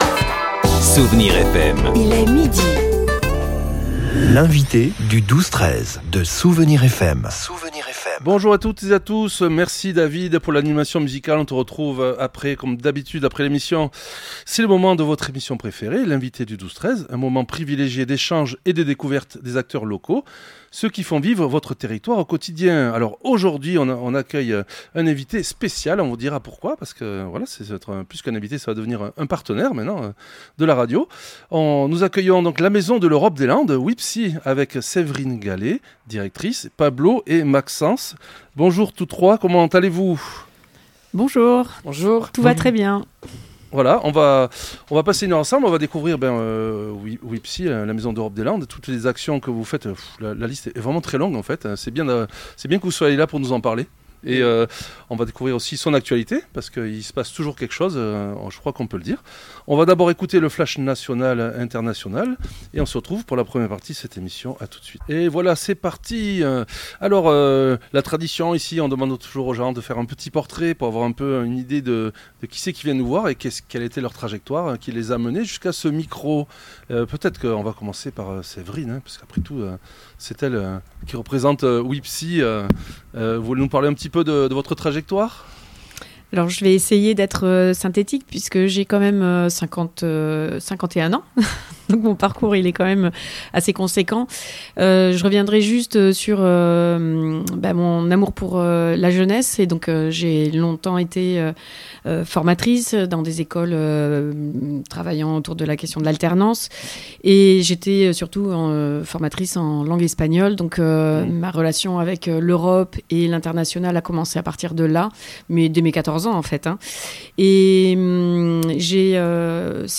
L’Europe était à l’honneur aujourd’hui dans notre studio… mais pas seulement ! Nous avons aussi parlé du développement de notre territoire et de son rayonnement, portés par des initiatives inspirantes.